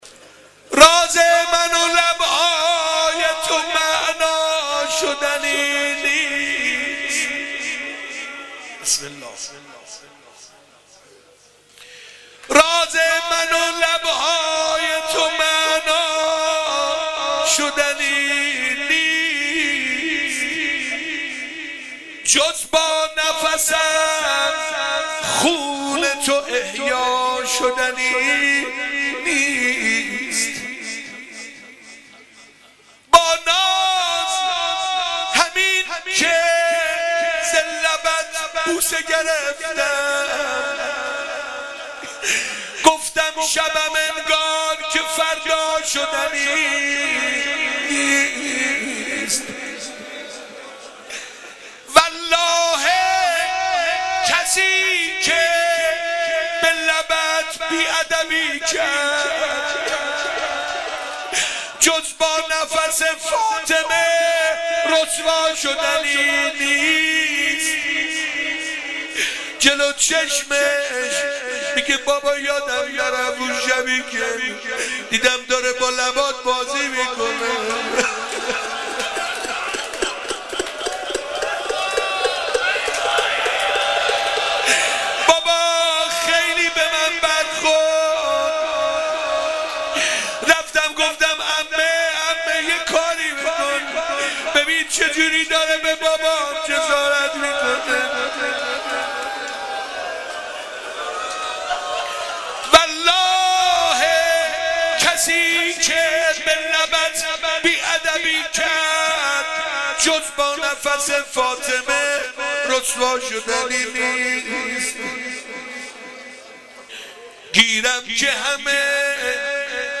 غزل و روضه حضرت رقیه